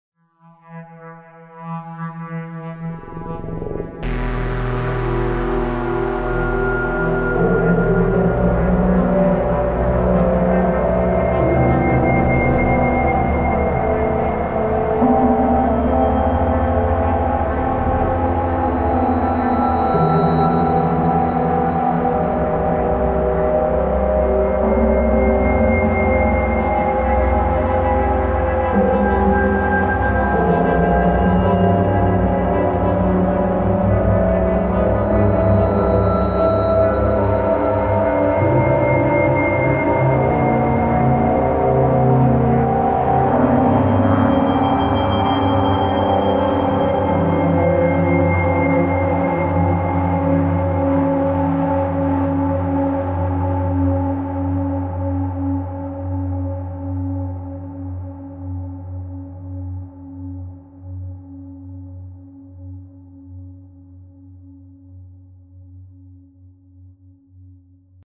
Möglichst depressiv.
Uh wow, zappenduster klingt das!